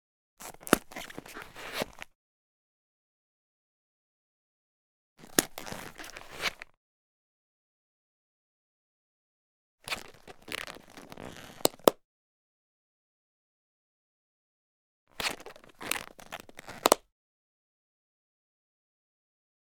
household
Camera Soft Leather Bag Open Top